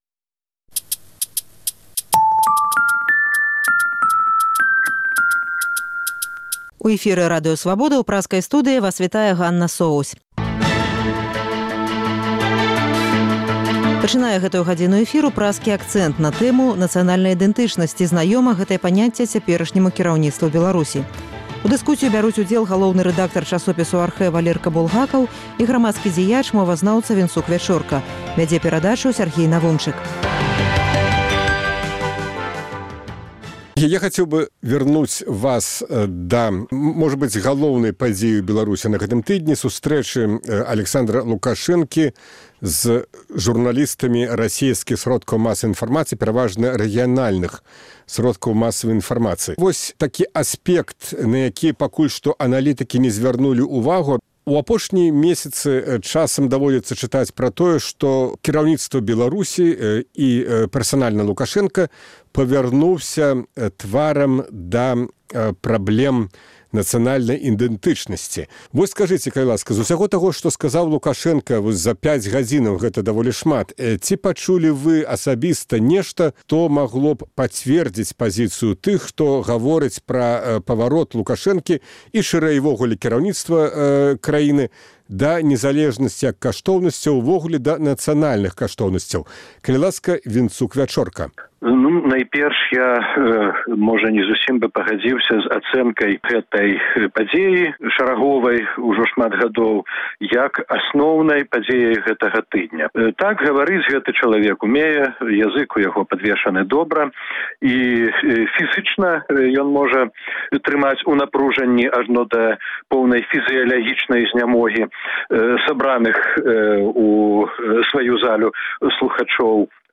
У дыскусіі бяруць удзел